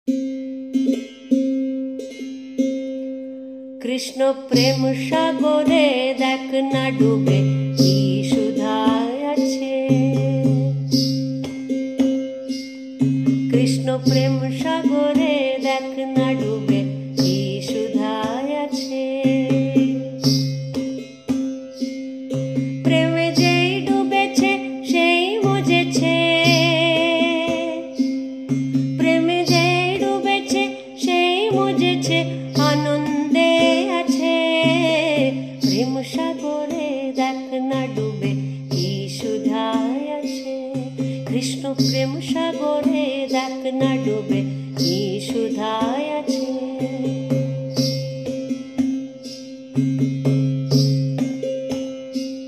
Anytime melody